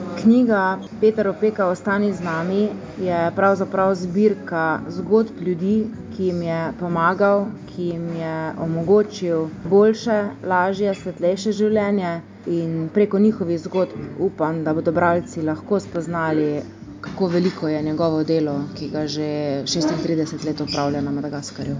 Dogodek je bil odlično obiskan, po njem pa so številni obiskovalci dvorano zapustili s hvaležnostjo, da živijo tukaj in tako, kot živijo.